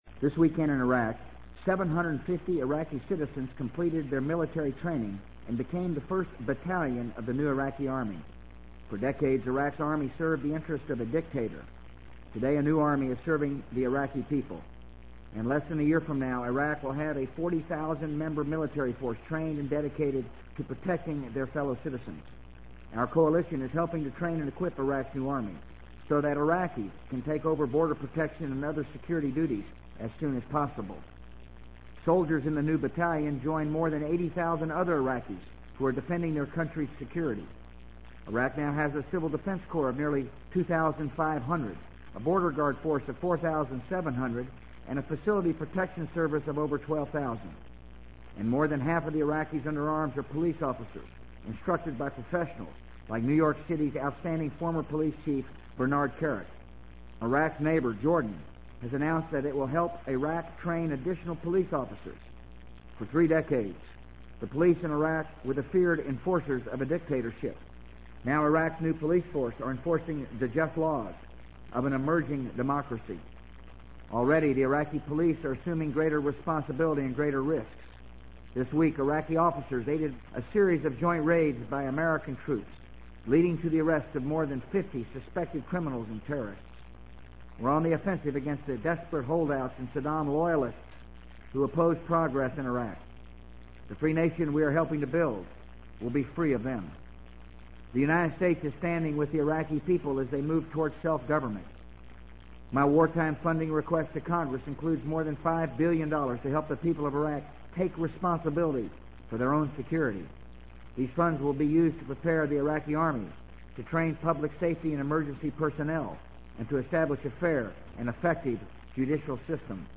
【美国总统George W. Bush电台演讲】2003-10-04 听力文件下载—在线英语听力室